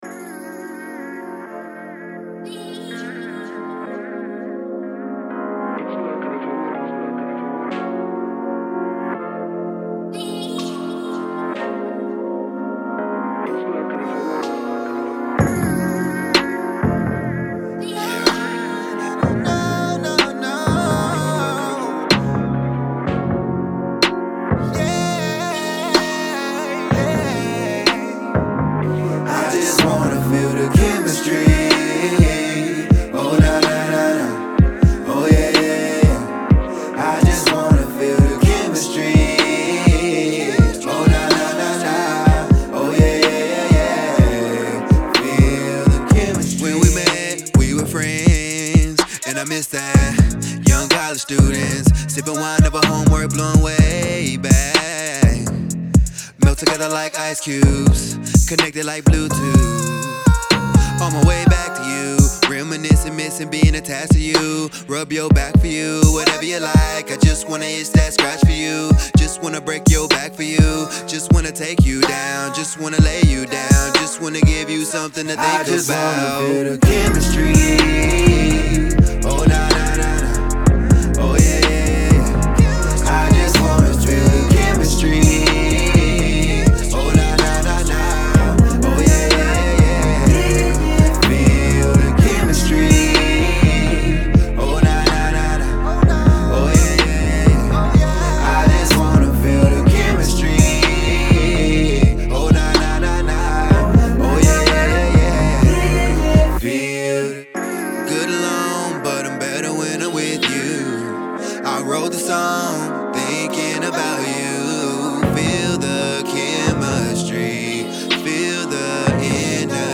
RnB
breaks into a rap to get his point across